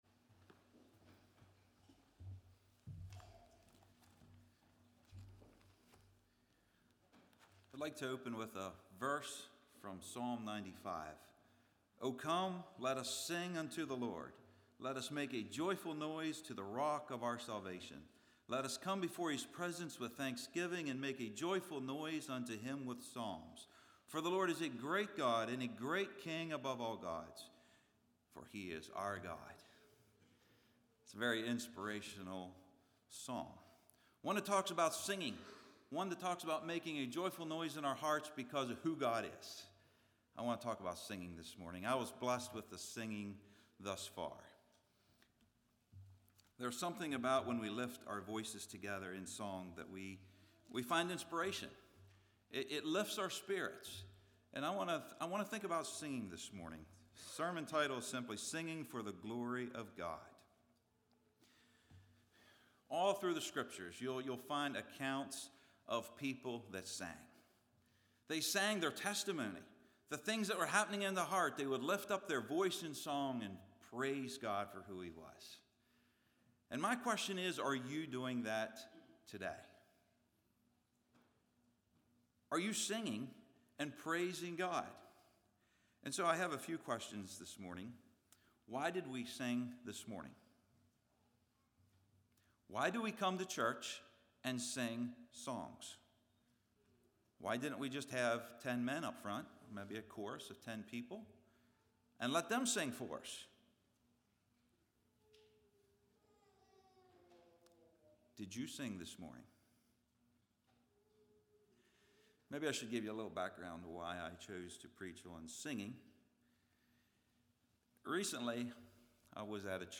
Play Now Download to Device Singing for the Glory of God Congregation: Calvary Speaker